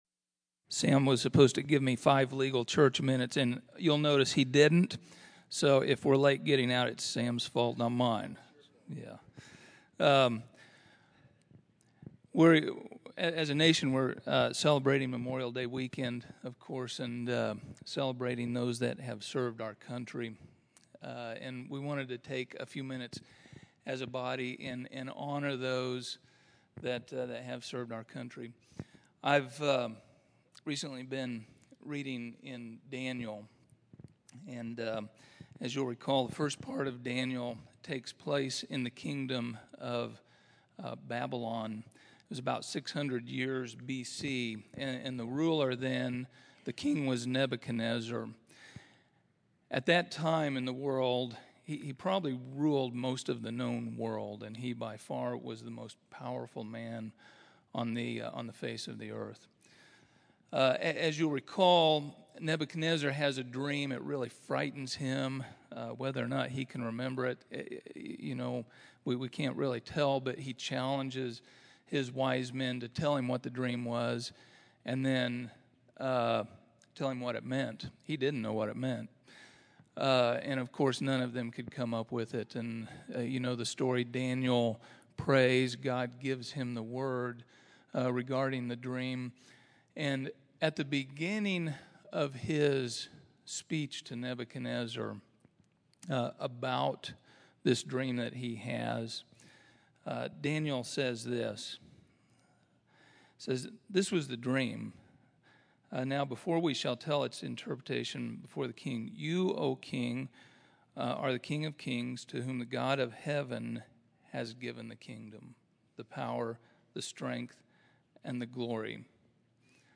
Category: Prayer Requests      |      Location: El Dorado